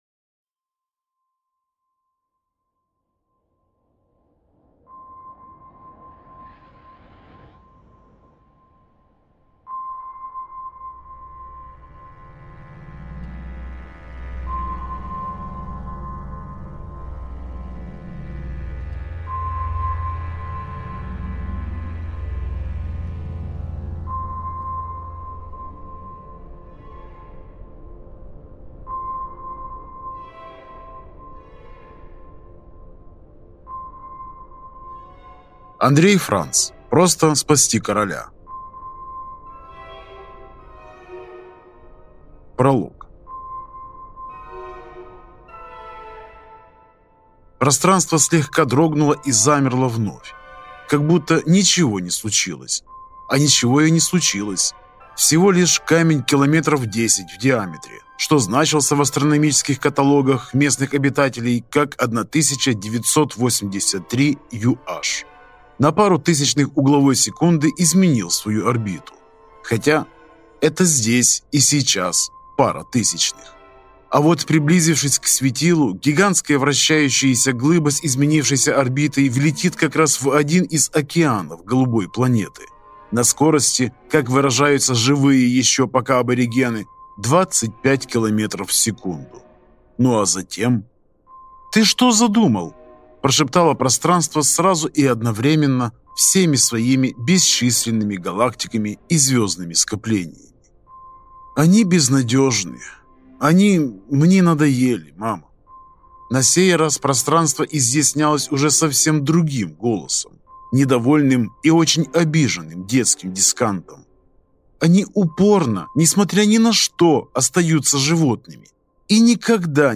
Аудиокнига Просто спасти короля | Библиотека аудиокниг